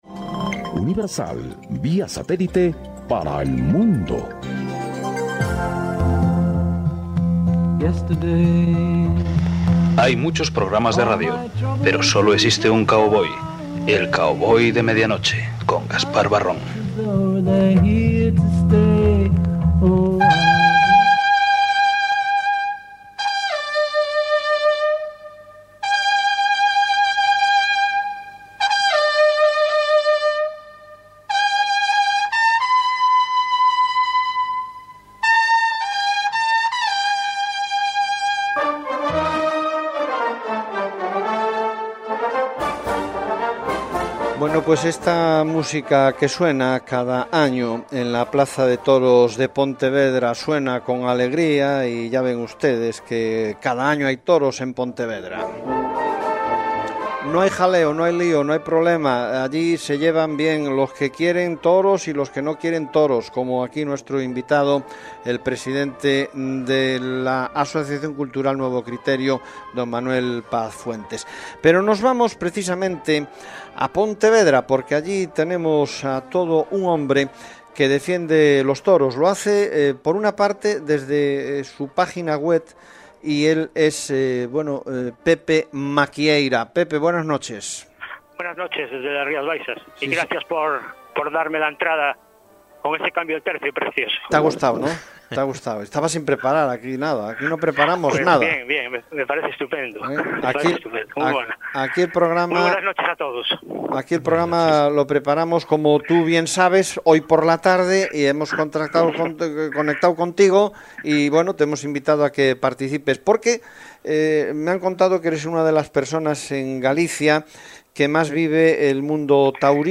Hemos estado en el programa de Unicversal Radio Coruña.